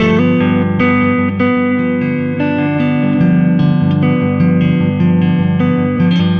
Weathered Guitar 04.wav